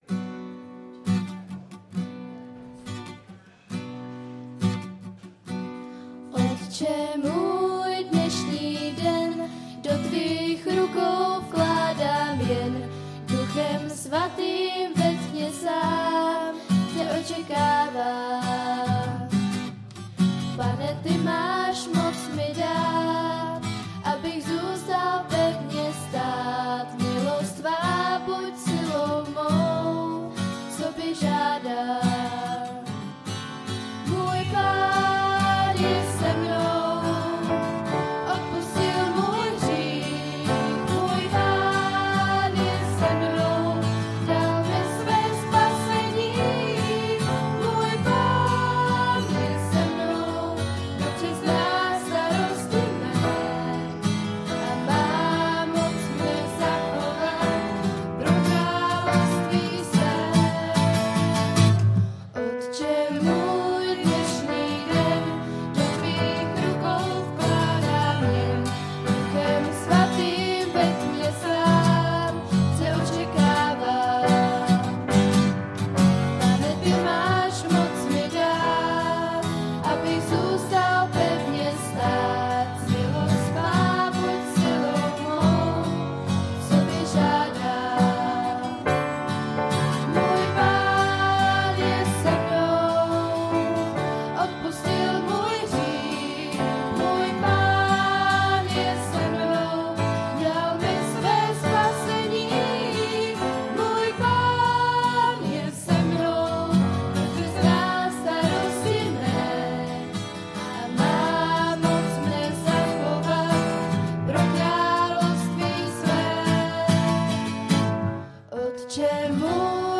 Kategorie: Nedělní bohoslužby přehrát / pozastavit Váš prohlížeč nepodporuje přehrávání audio souborů. stáhnout mp3